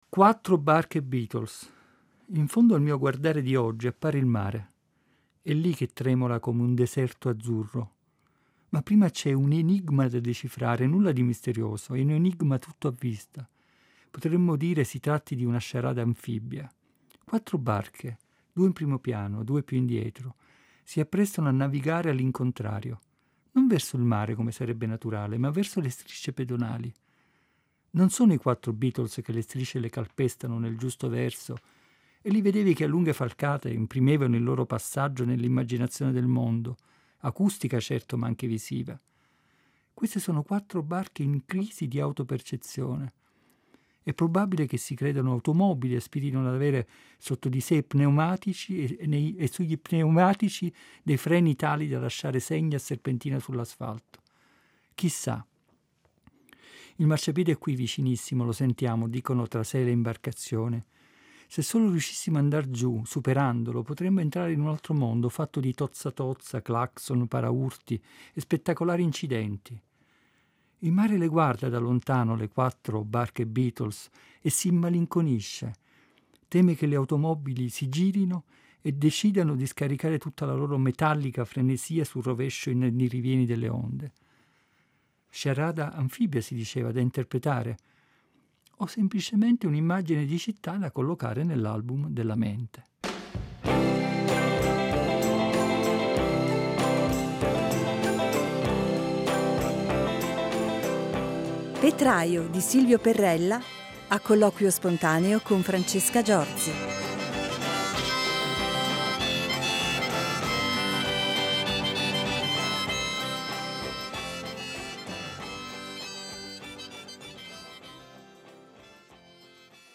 le ha lette e commentate in dialogo sornione e fertile